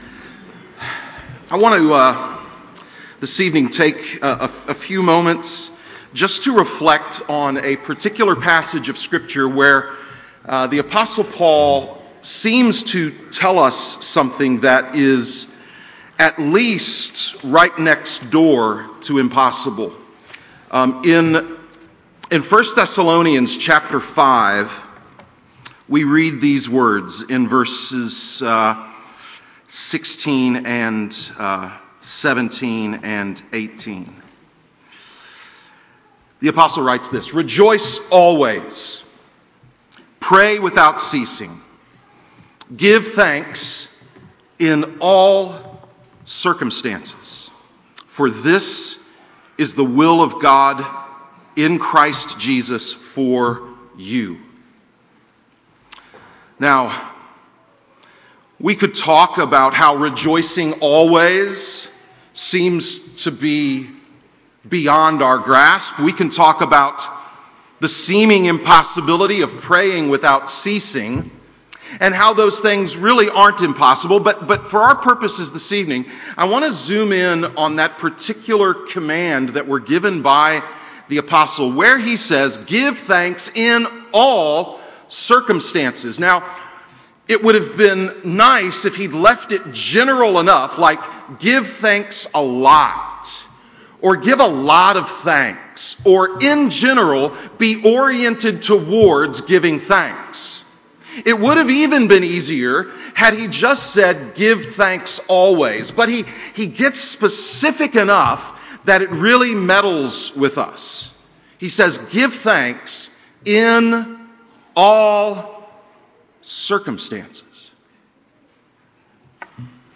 Thanksgiving Evening Service 2025